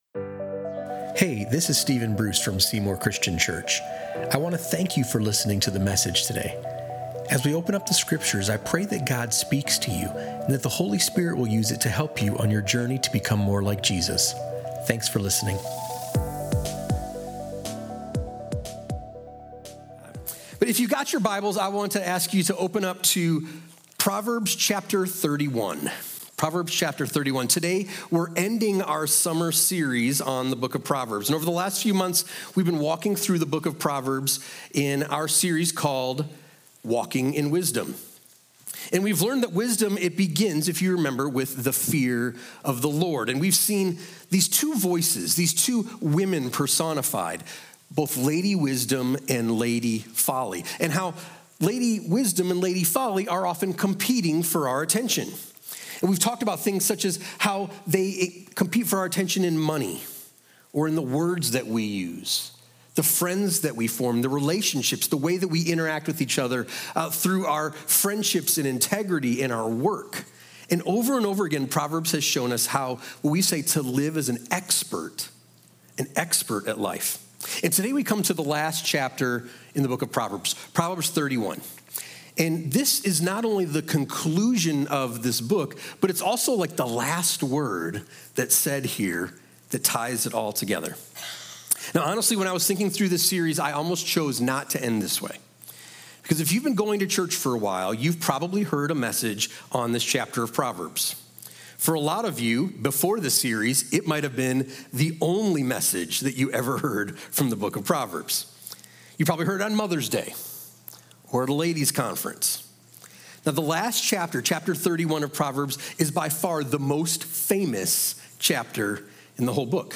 Explore Proverbs 31 with Seymour Christian Church in our Walking in Wisdom series—a picture of godly wisdom: trustworthy, generous, and confident in Christ.